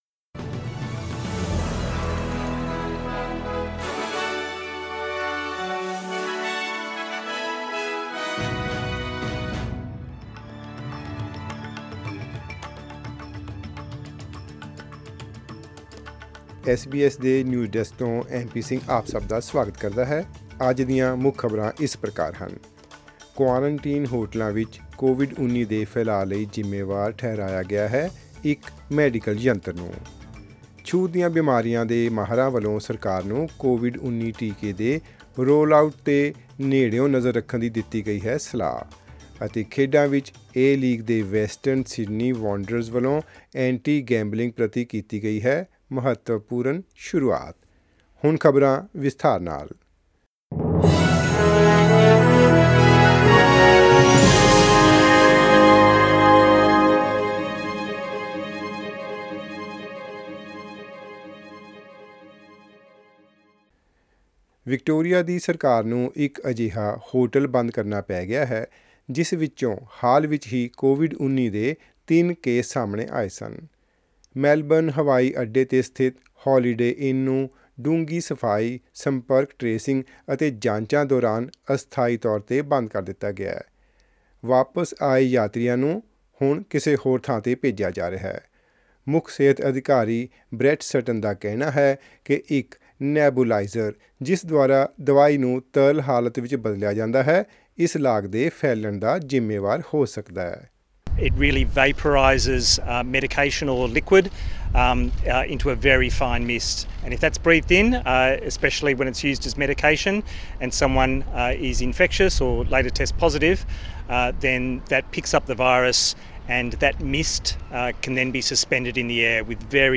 SBS Punjabi News 10 Feb: A medical device blamed for a COVID-19 outbreak at a quarantine hotel